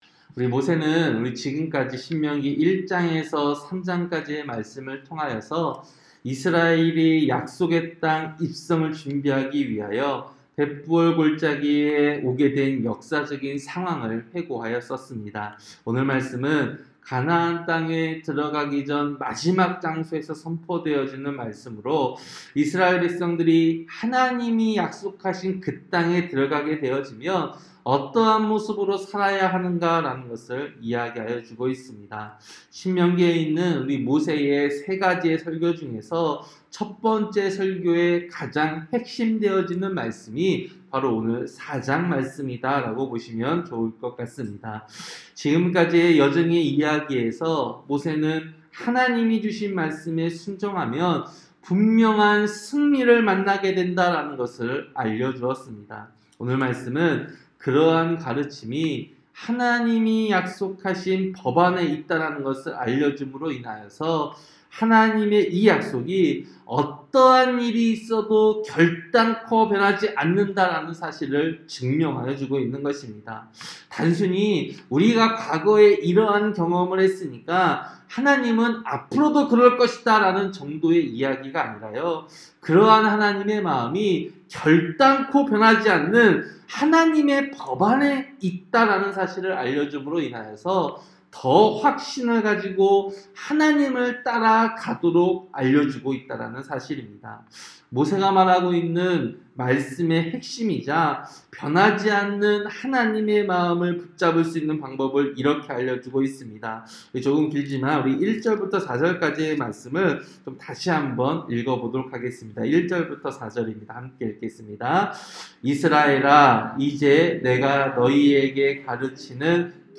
새벽설교-신명기 4장